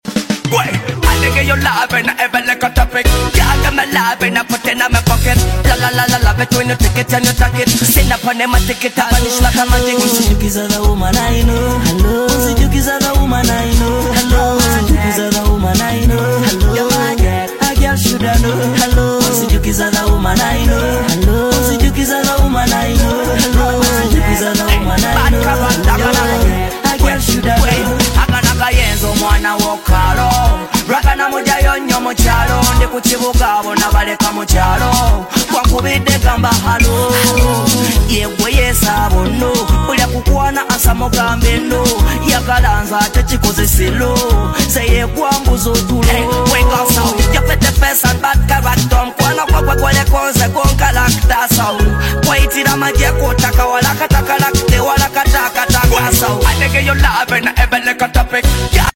yeah sound effects free download